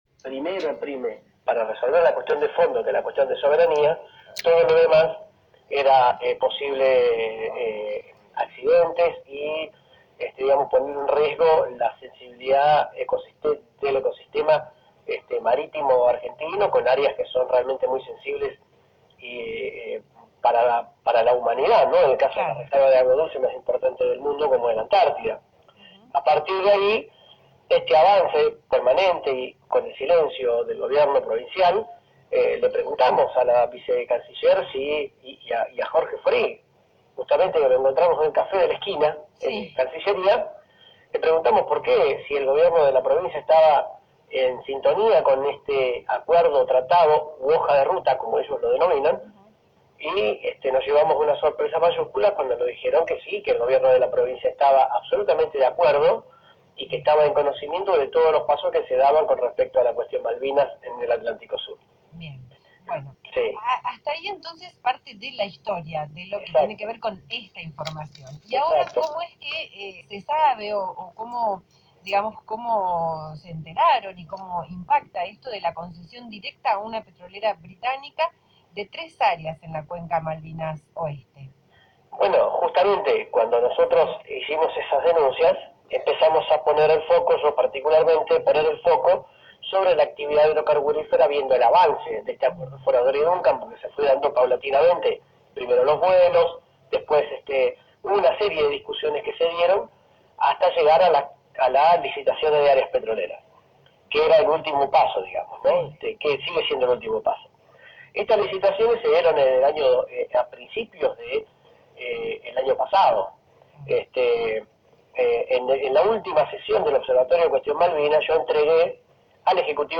la entrevista emitida en el programa Tarde pero Seguro